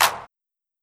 Clap (Rockin').wav